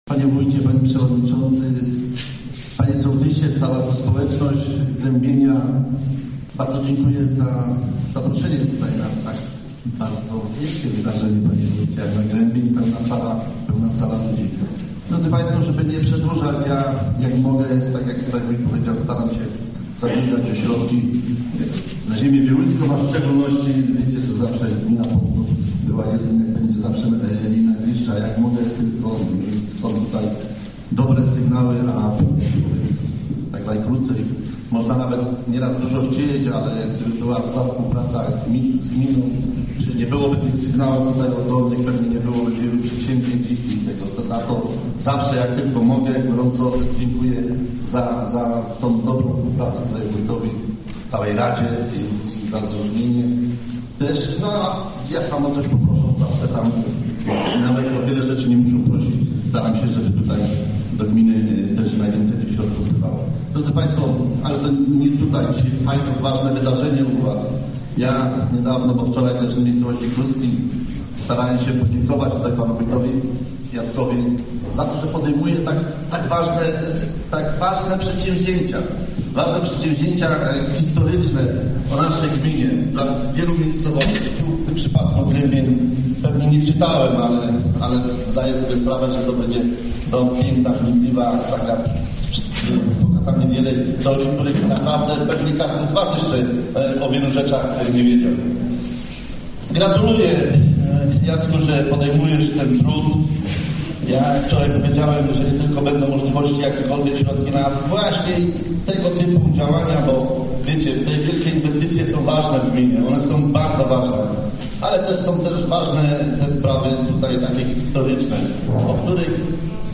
Posłuchaj całej rozmowy: Gościem Radia ZW był Paweł Madeła, radny Rady Gminy w Pątnowie, sołtys Grębienia